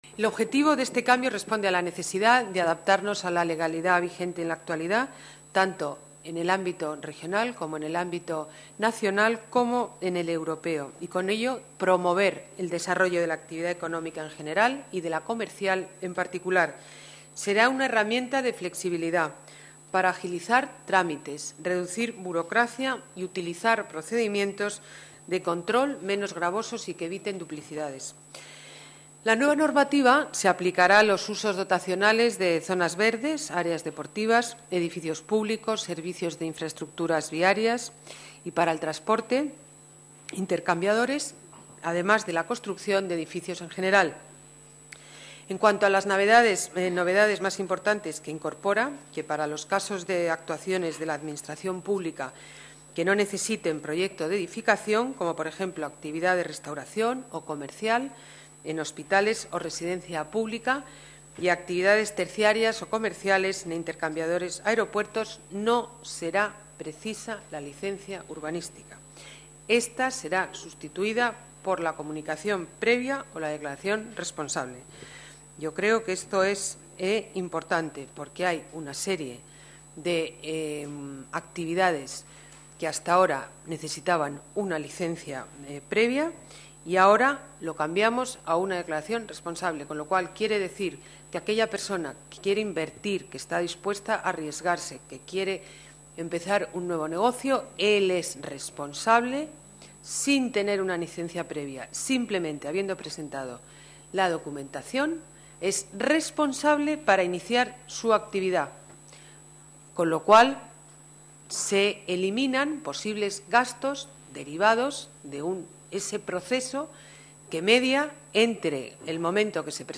Nueva ventana:Alcaldesa: Licencias urbanisticas